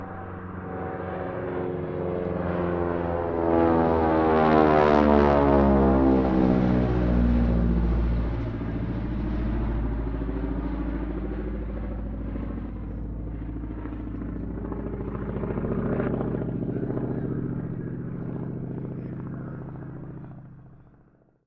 Звуки военных самолетов
На этой странице собраны звуки военных самолетов разных типов: от рева реактивных двигателей до гула винтовых моделей.
Военные летчики отрабатывают навыки в небе